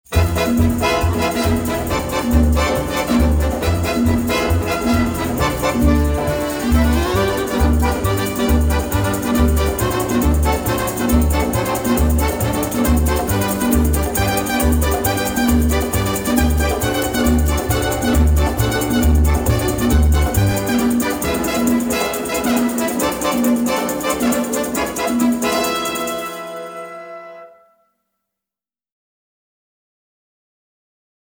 minimal, obsessive style
in clean monaural sound